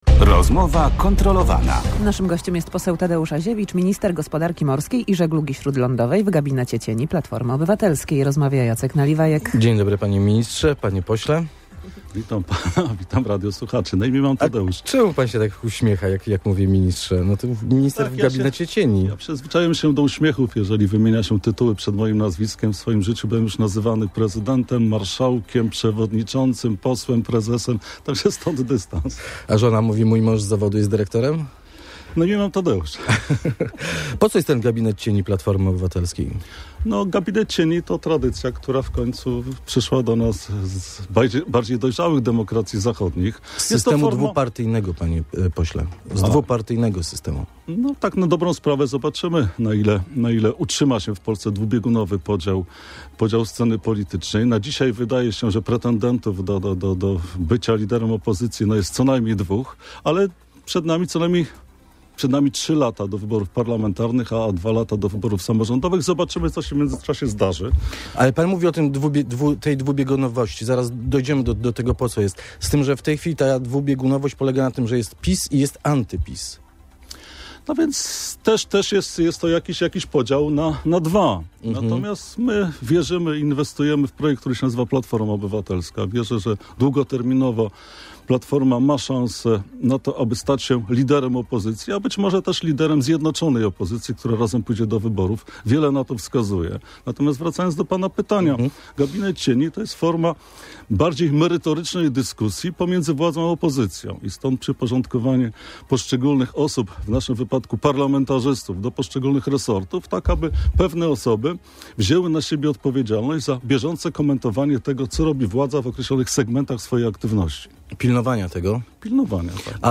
Gościem Rozmowy kontrolowanej był poseł Platformy Obywatelskiej Tadeusz Aziewicz, Minister Gospodarki Morskiej i Żeglugi Śródlądowej w Gabinecie cieni PO.